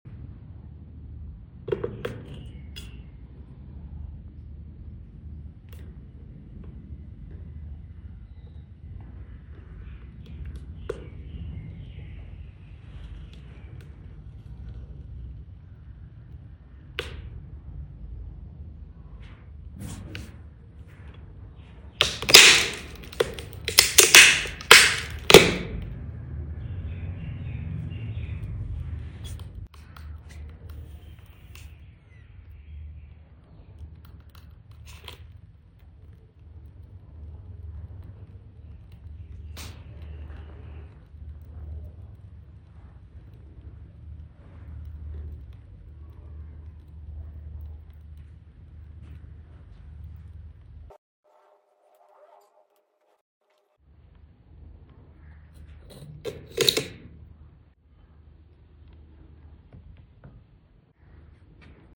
1000°C Red Hot Metal Ball Sound Effects Free Download